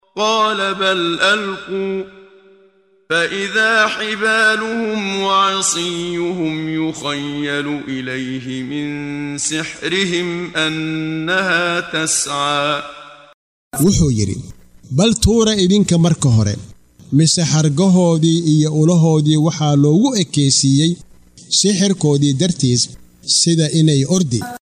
Waa Akhrin Codeed Af Soomaali ah ee Macaanida Suuradda Ta Ha oo u kala Qaybsan Aayado ahaan ayna la Socoto Akhrinta Qaariga Sheekh Muxammad Siddiiq Al-Manshaawi.